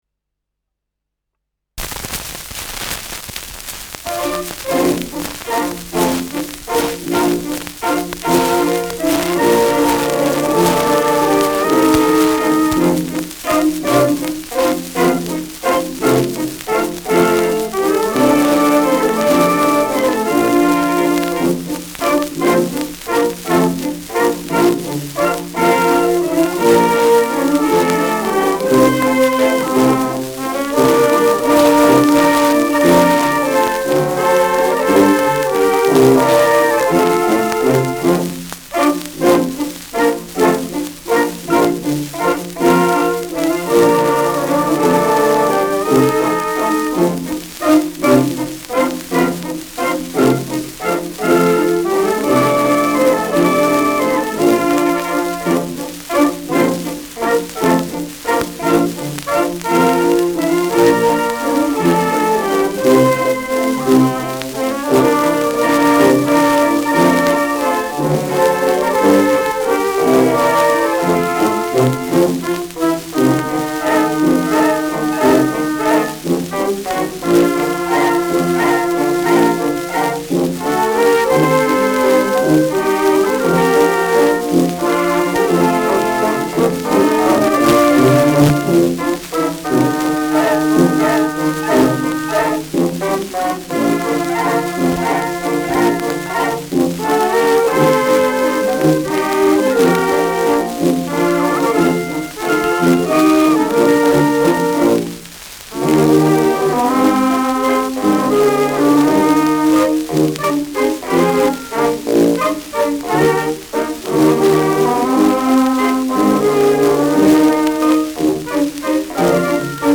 Schellackplatte
Rauschen präsent
Kapelle Peuppus, München (Interpretation)
[München] (Aufnahmeort)